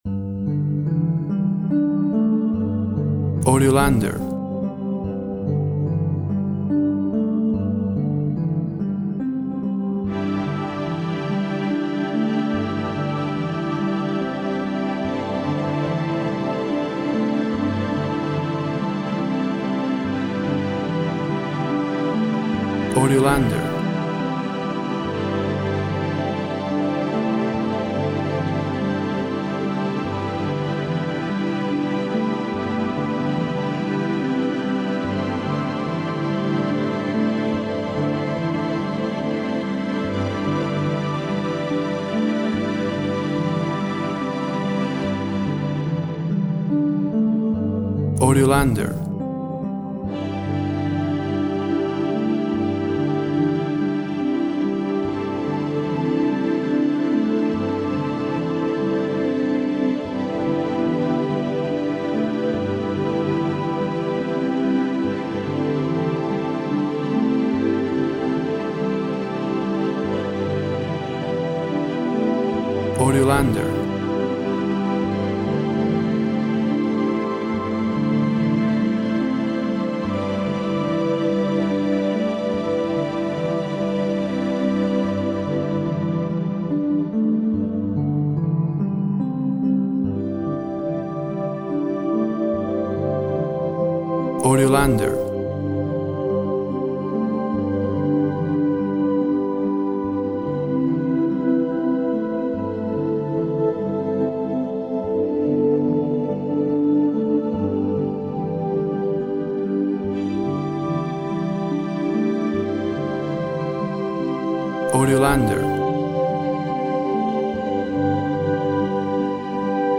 Synth harp and strings create a gentle atmosphere.
Tempo (BPM) 72